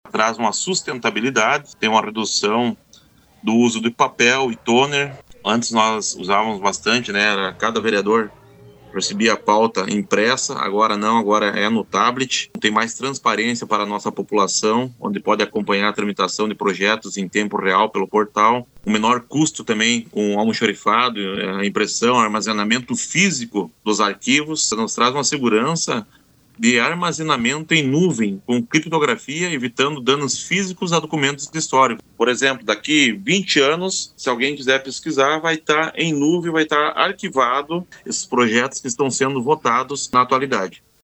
O presidente do Legislativo bozanense, Maurício Vianna, do PL, durante entrevista ontem à noite no programa de Bozano, pela RPI, disse que essas medidas agilizam os processos na casa, trazem economia e melhoria no armazenamento de informações.